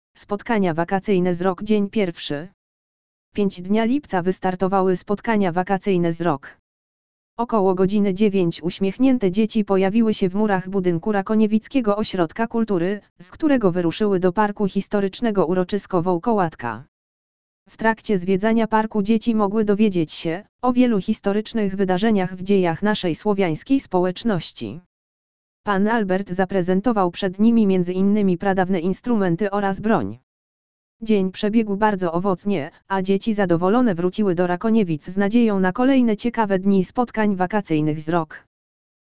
lektor_audio_spotkania_wakacyjne_z_rok_dzien_pierwszy.mp3